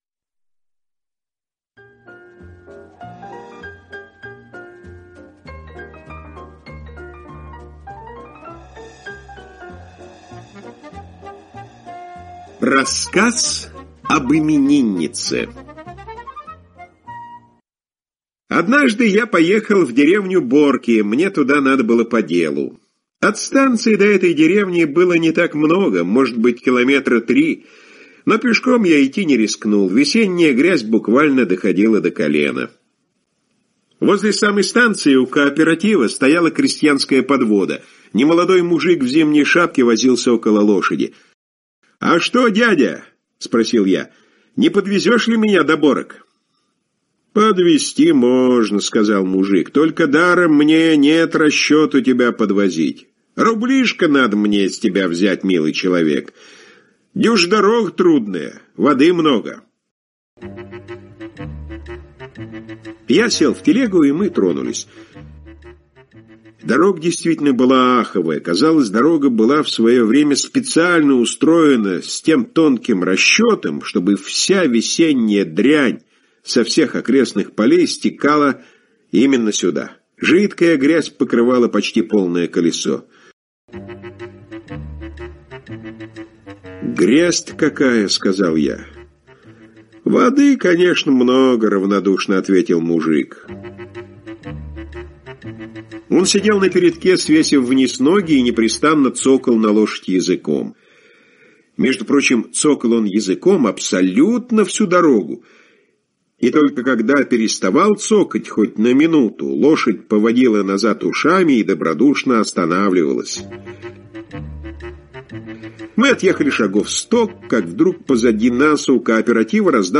Ecoutez en russe - Слушайте: